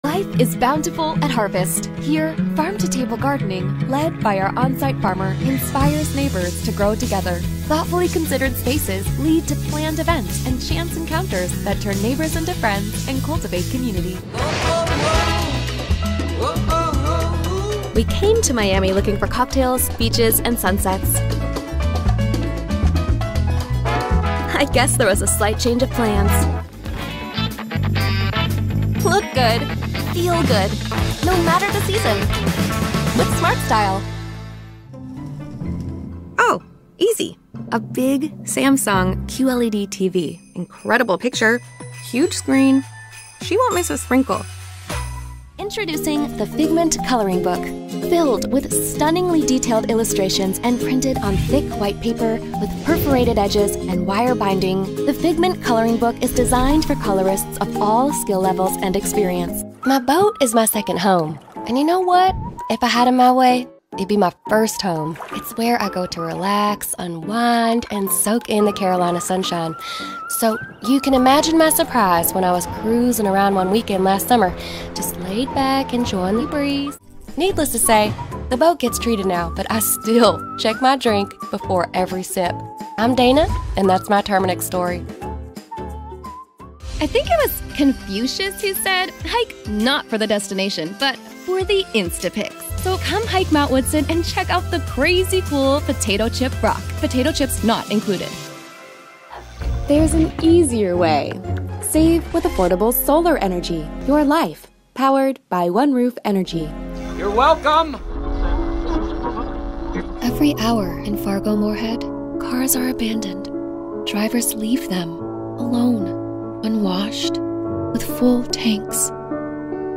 Commercial Demo